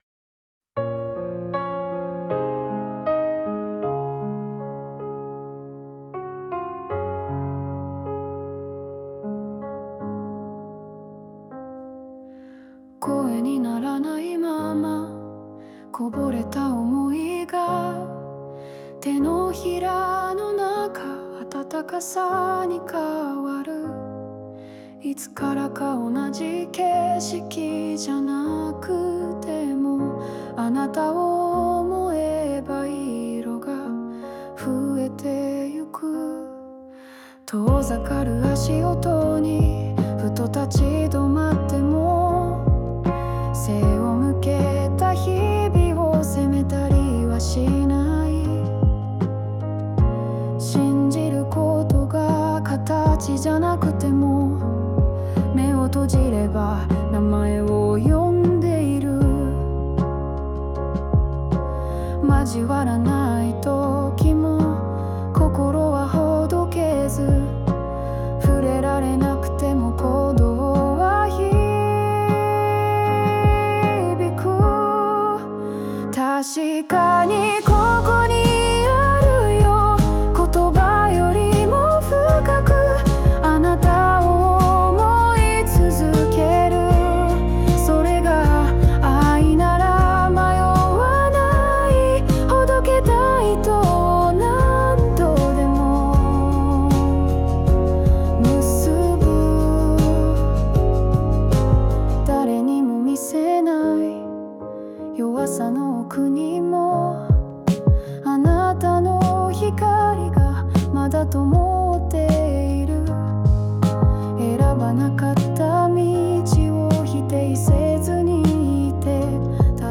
邦楽女性ボーカル著作権フリーBGM ボーカル
著作権フリーオリジナルBGMです。
女性ボーカル（邦楽・日本語）曲です。